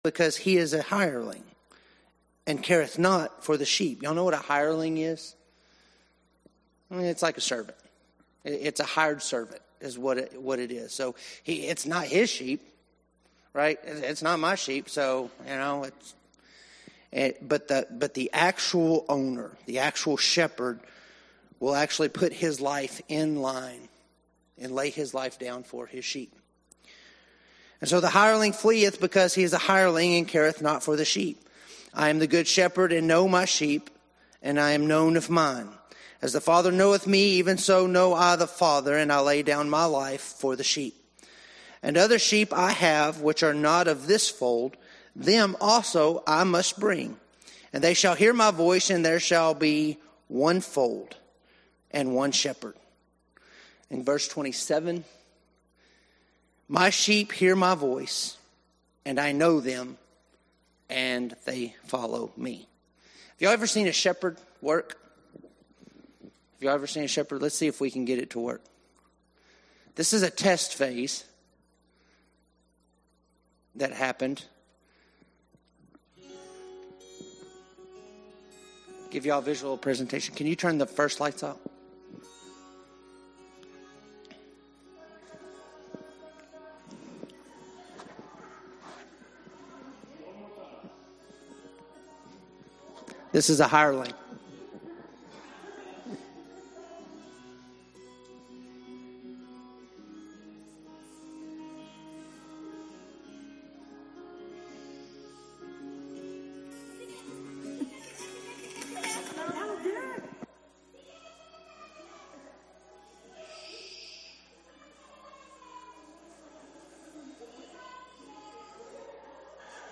Adult Sunday School Class